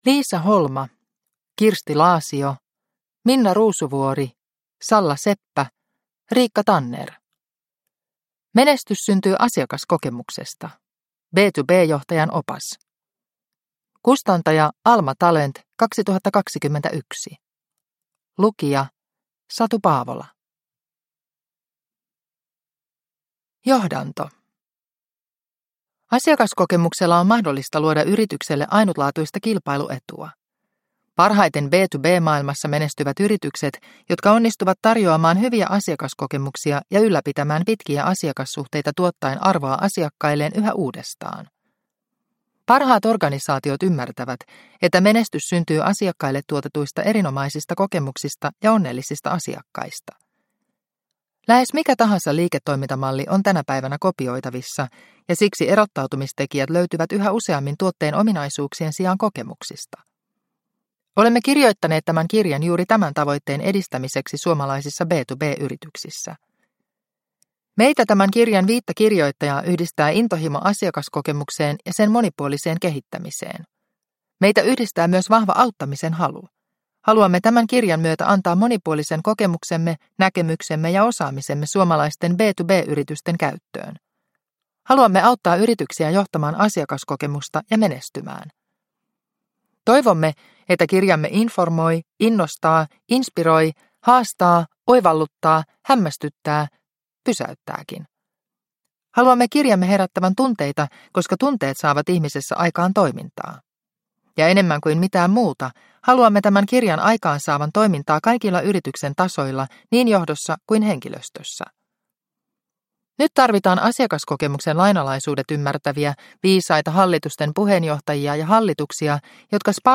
Menestys syntyy asiakaskokemuksesta – Ljudbok – Laddas ner